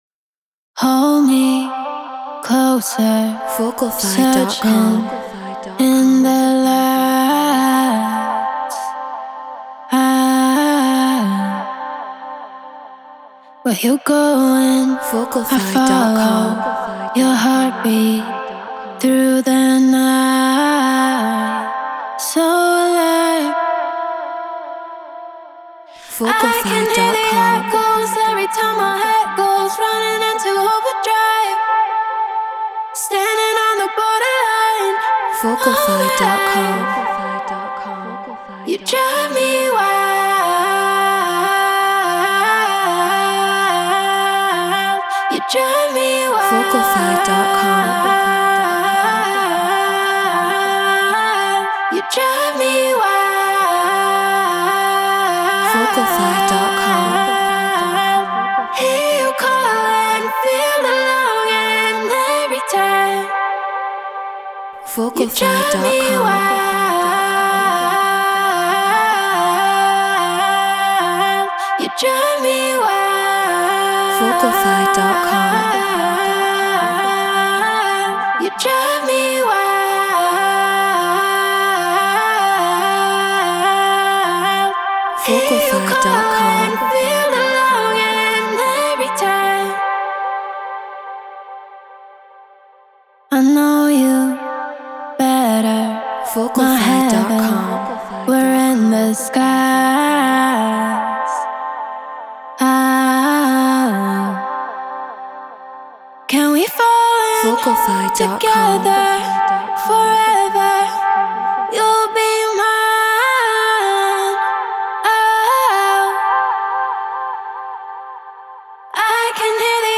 Stutter / Eurodance 145 BPM Dmin
Shure KSM 44 Apollo Twin X Pro Tools Treated Room